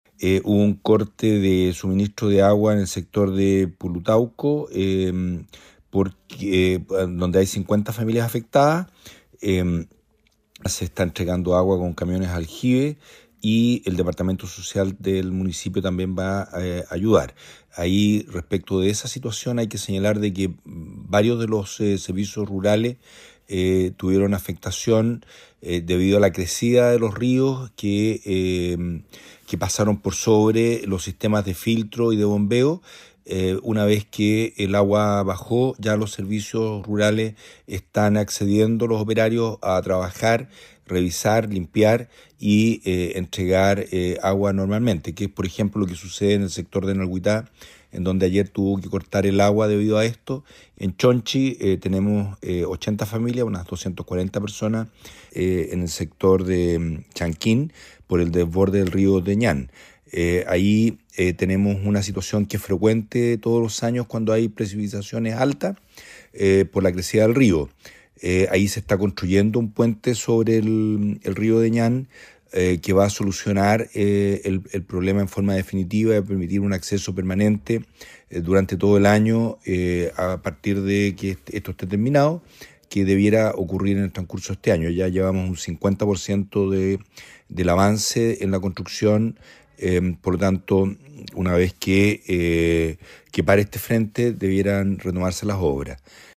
El inédito registro de agua lluvia caída tuvo consecuencias en varios servicios sanitarios rurales, con el consiguiente corte en el suministro de agua potable, principalmente en Dalcahue y Castro, señaló la autoridad.